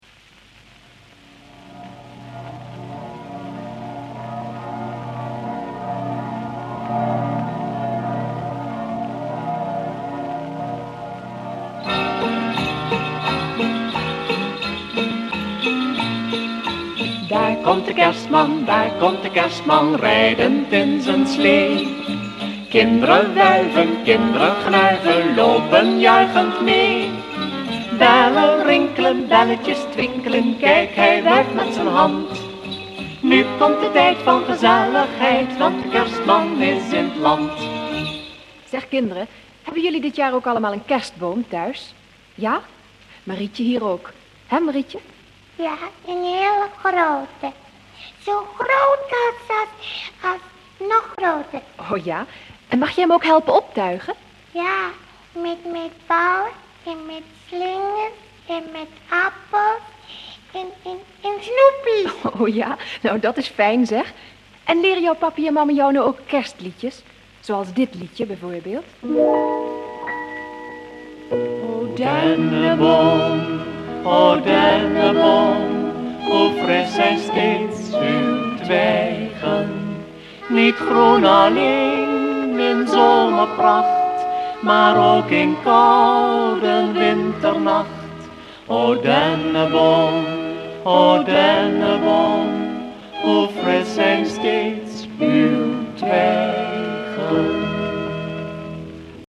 Liedjes met gesproken intro's
25 cm LP | 78 toeren